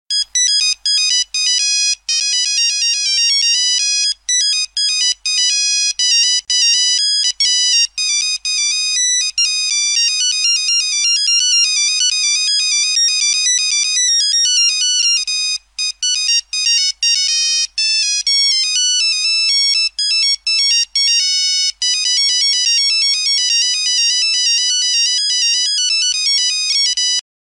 Старая восьмибитная музыка